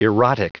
Prononciation du mot erotic en anglais (fichier audio)
Prononciation du mot : erotic